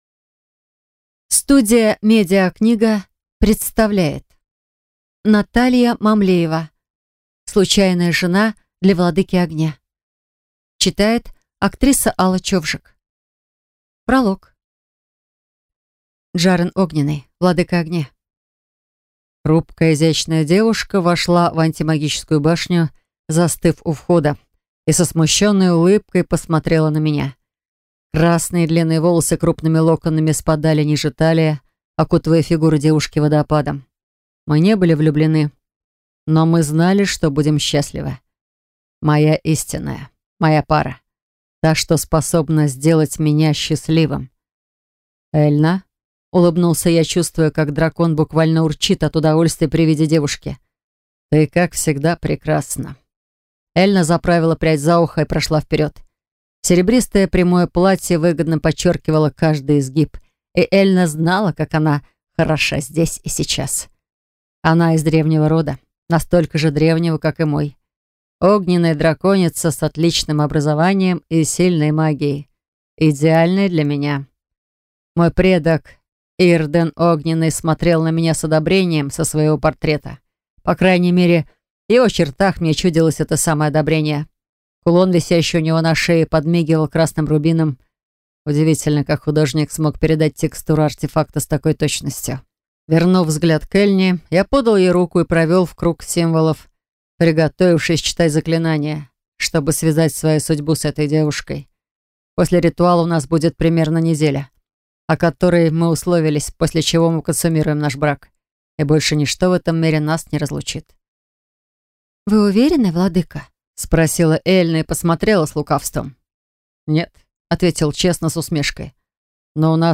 Аудиокнига Случайная жена для Владыки Огня | Библиотека аудиокниг